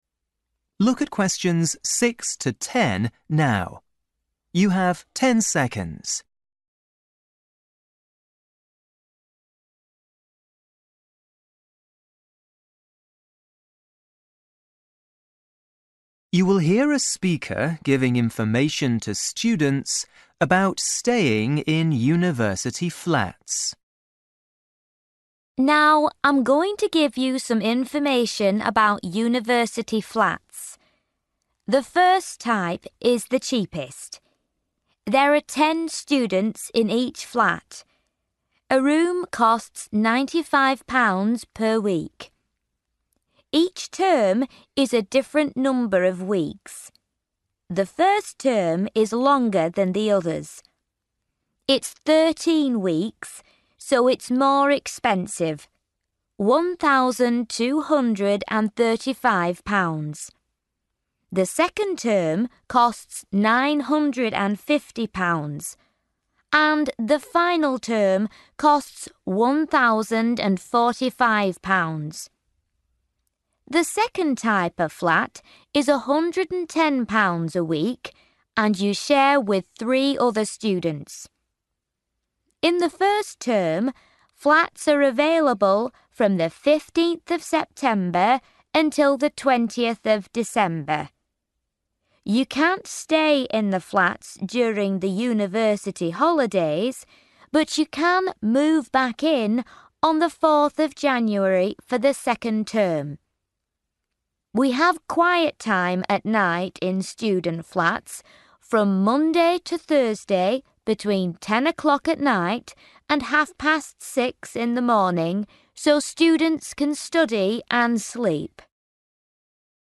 You will hear a woman talking to a group of students about the library.